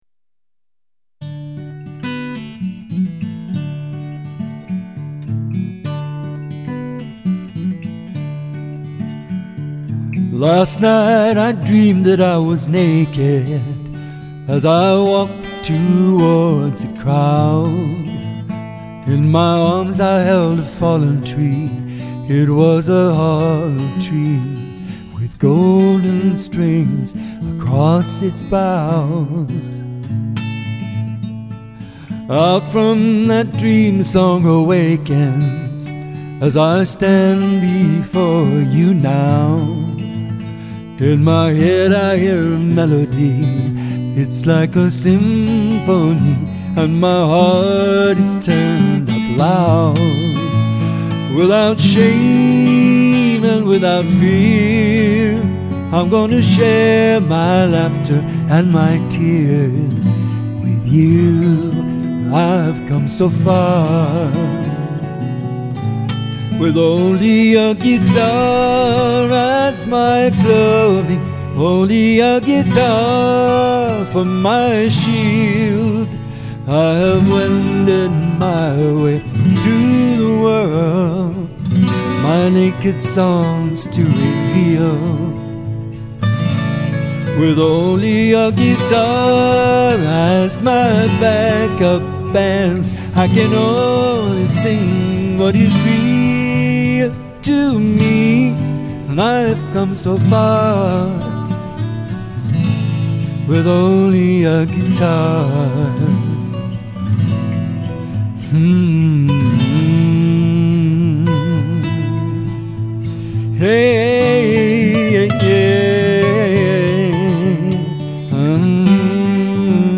I recorded it on my Tascam SD24. There are 6 tracks of acoustic guitar recorded with Shure SM81 with a bit of TASCAM reverb and some light EQ and 1 vocal track recorded with AKG SolidTube with light EQ and some t.c.electronic VOCAL REVERB.